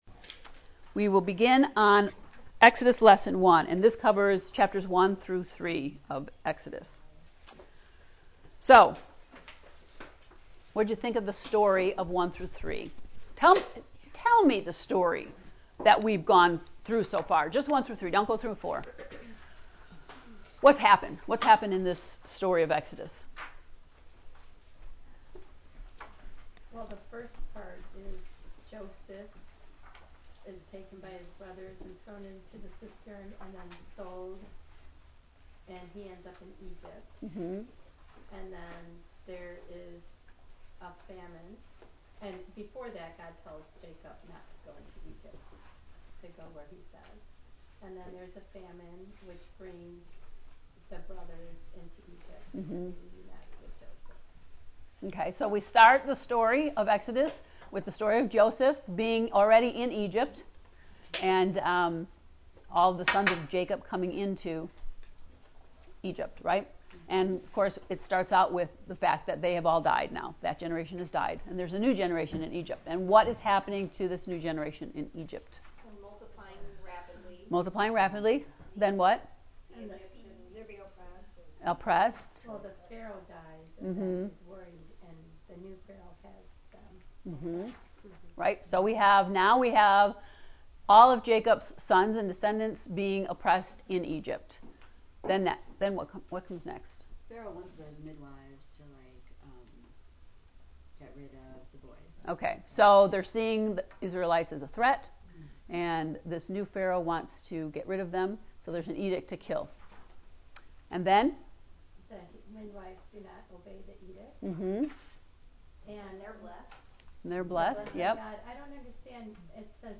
To listen to the Exodus lesson 1 lecture ” Behind the Scenes”, click here: (1:53:02)